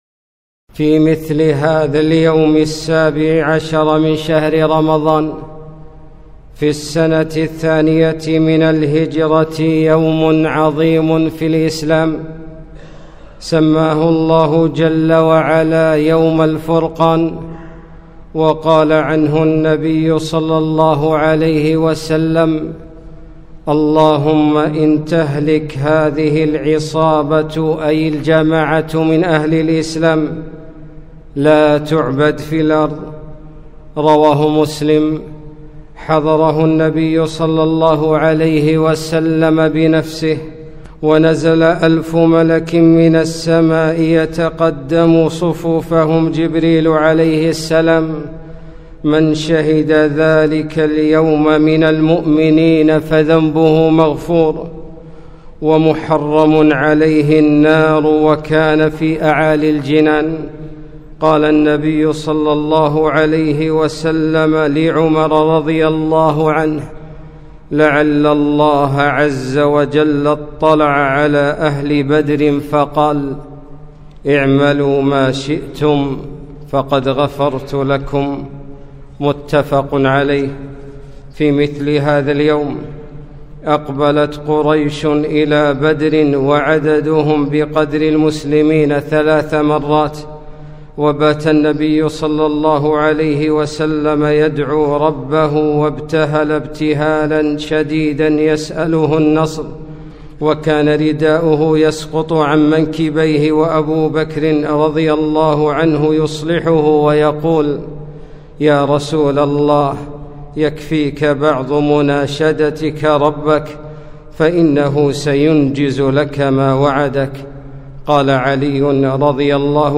خطبة - غزوة بدر الكبرى